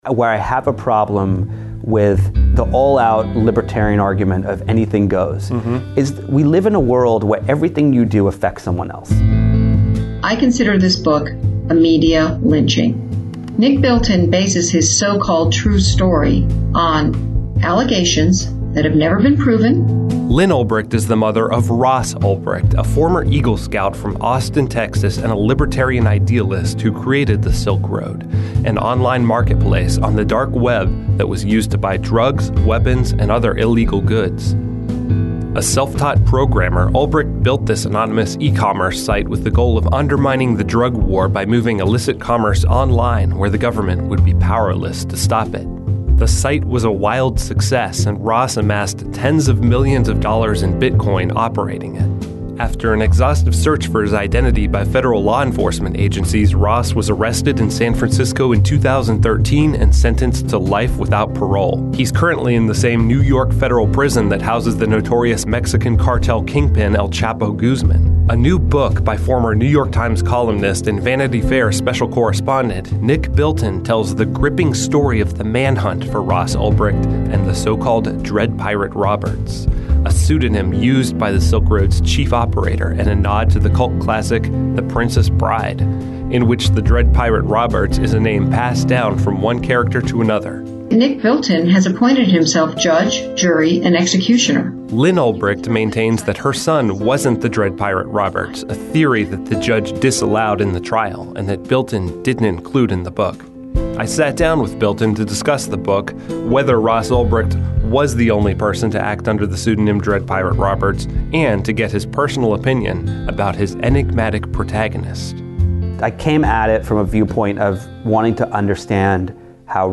A review of American Kingpin and an interview with the author.